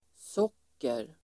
Uttal: [s'åk:er]